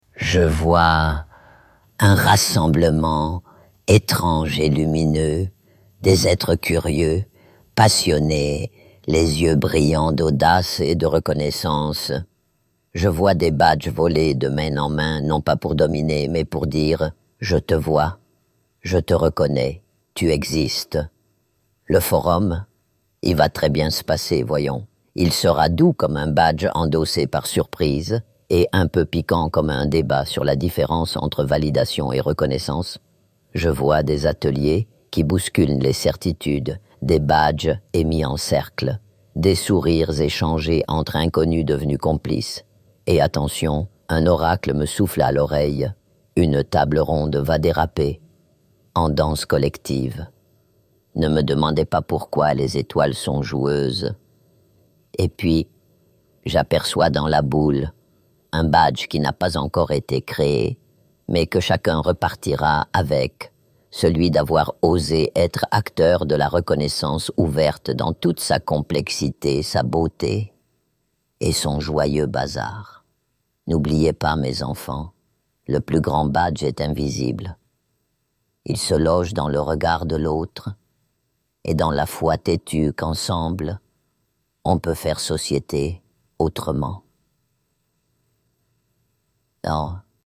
3. Sketchs et Parodies